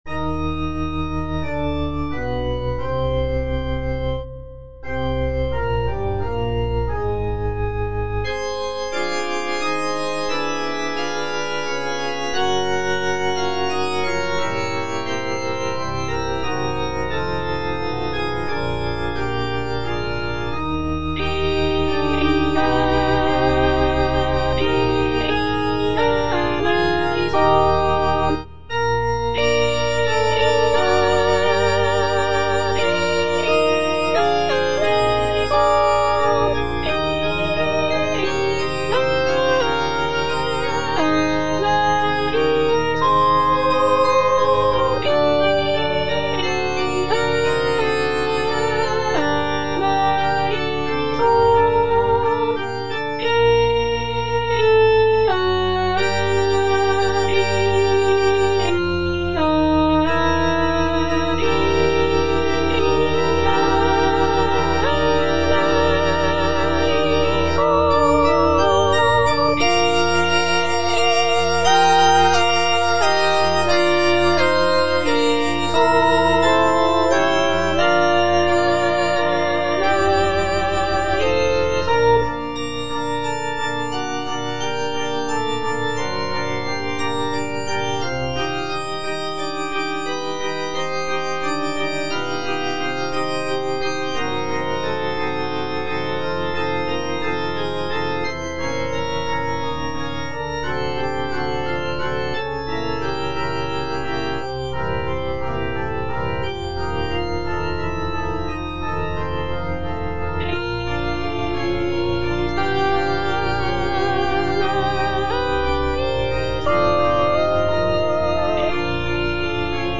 G. FAURÉ, A. MESSAGER - MESSE DES PÊCHEURS DE VILLERVILLE Kyrie (All voices) Ads stop: auto-stop
It was written in 1881 for a choir of local fishermen in Villerville, a small village in Normandy, France. The composition is a short and simple mass setting, featuring delicate melodies and lush harmonies.